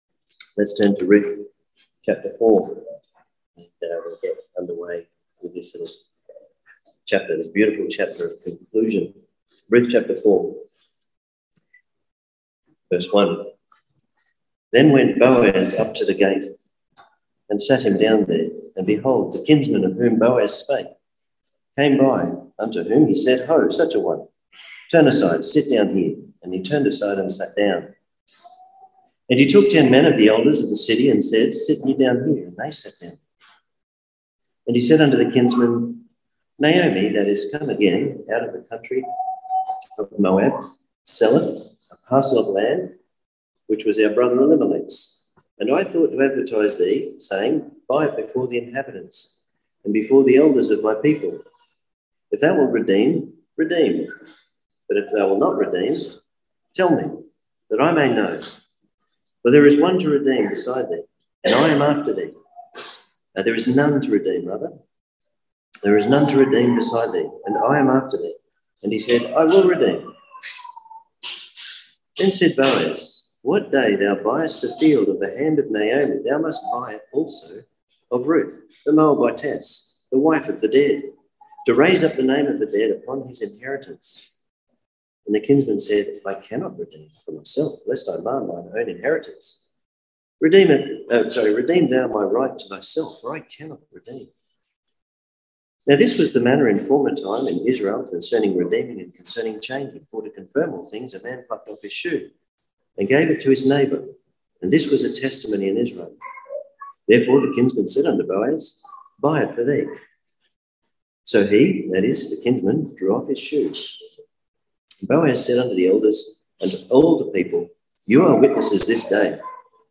(Cooroy Conference 2022)